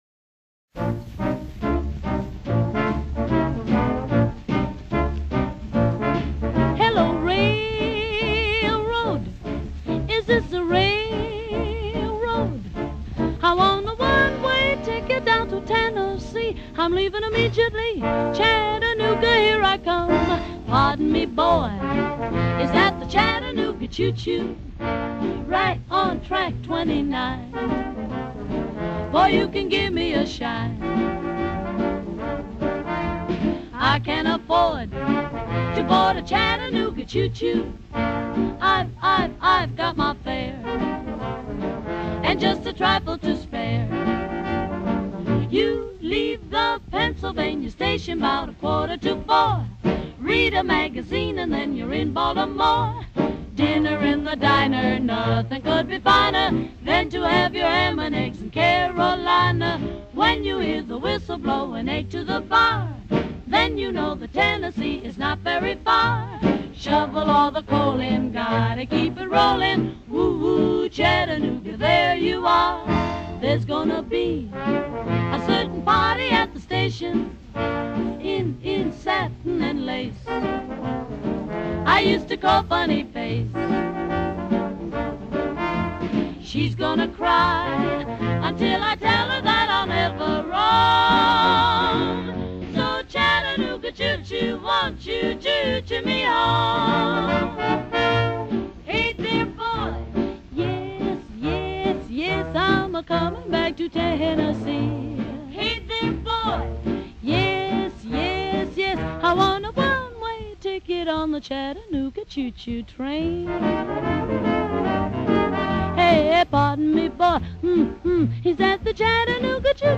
Chœur d’hommes fondé en 1860
Interprété par le Chœur du Léman en : 1996, 2006, 2013, 2022
H10078-Live.mp3